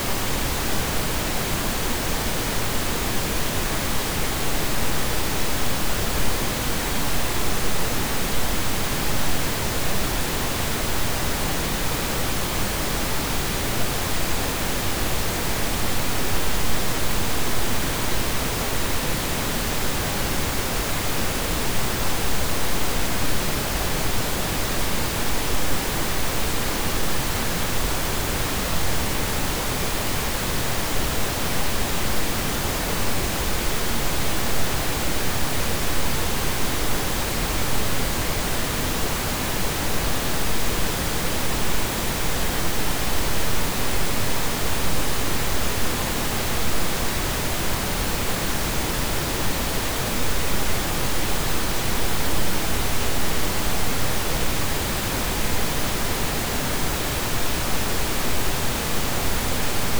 PinkNoiseFullSpectrum.wav